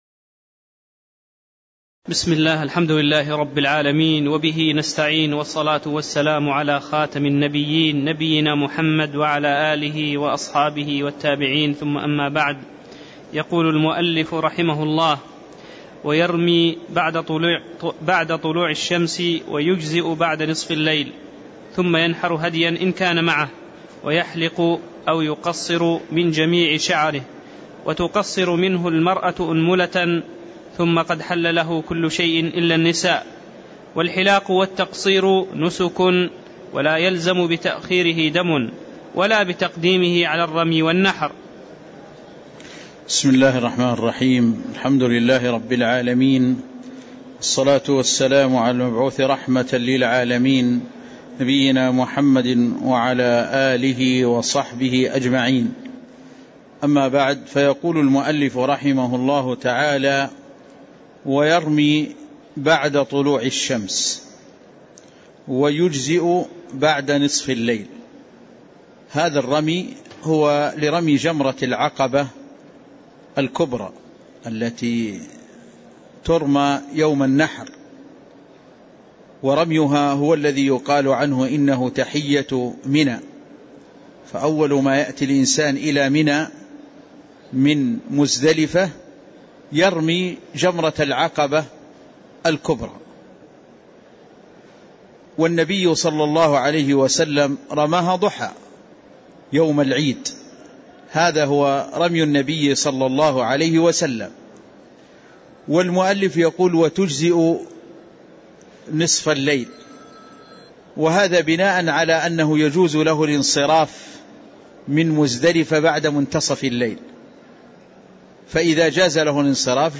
تاريخ النشر ٦ ذو الحجة ١٤٣٥ هـ المكان: المسجد النبوي الشيخ